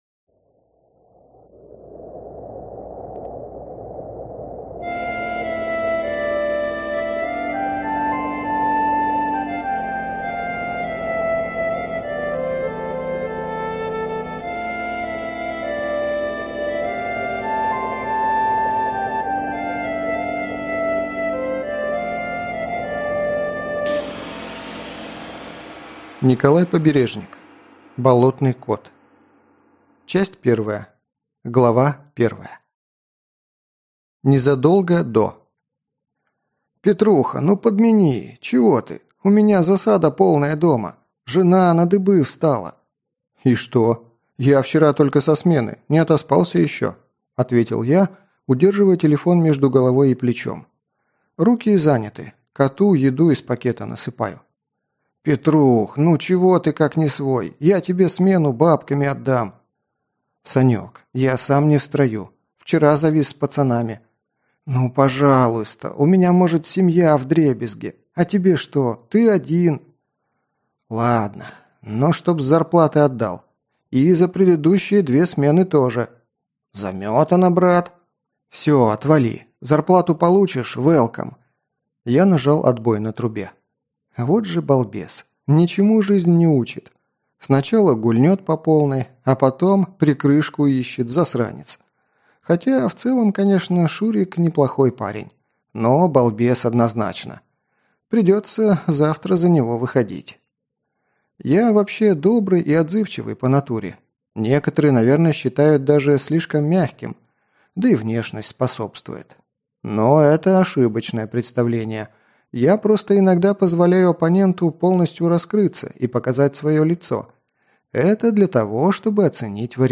Aудиокнига Болотный кот